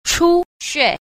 8. 出血 – chūxiě – xuất huyết (chảy máu)